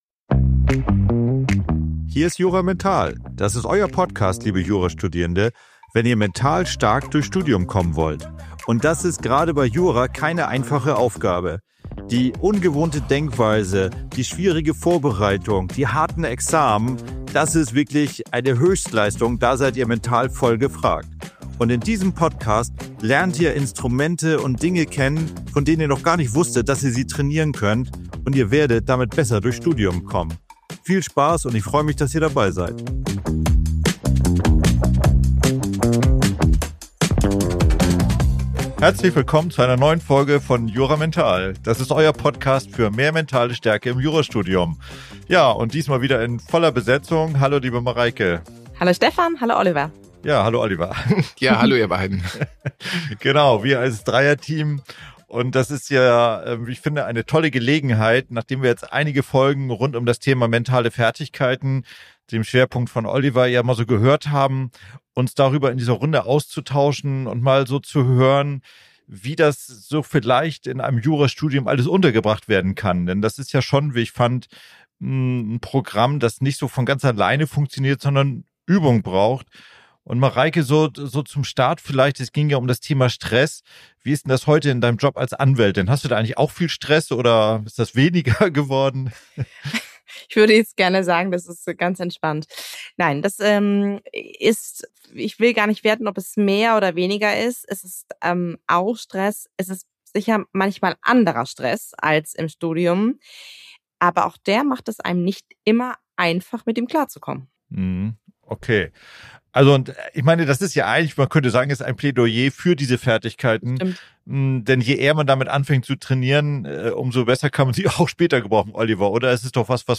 Die Juristin, der Psychotherapeut und der Coach bringen auf den Punkt, wobei es bei diesem erfolgreichen Trainingsprogramm für Jurastudierende geht: um eine Entscheidung, eine Haltungsveränderung. Wenn du dich darauf einlässt, kannst du einen überraschenden Gamechanger in Sachen Studienstress und Prüfungsangst erleben.